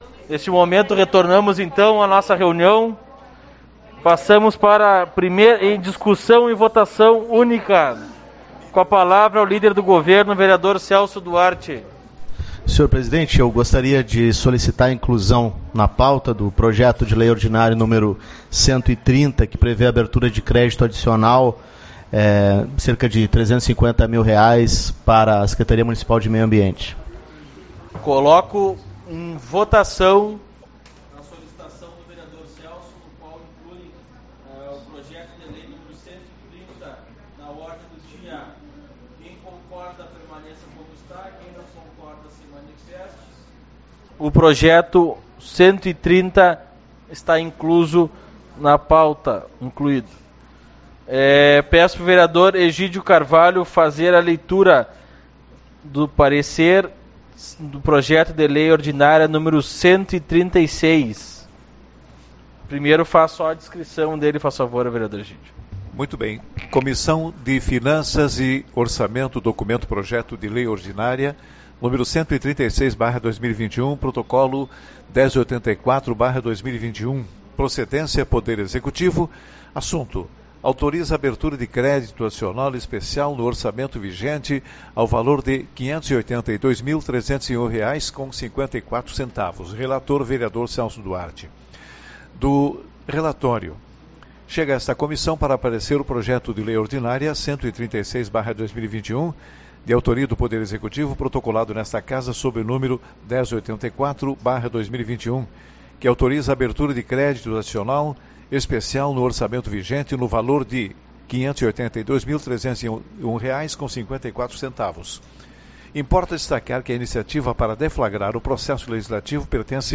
04/11 - Reunião Ordinária